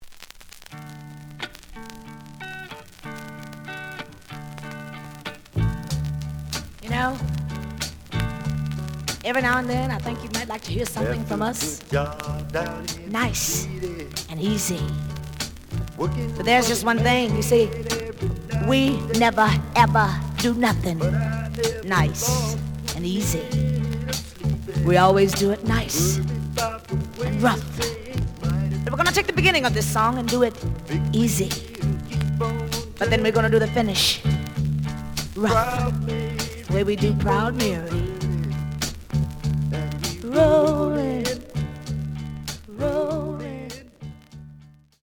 The audio sample is recorded from the actual item.
●Genre: Soul, 70's Soul
Some noise on A side.)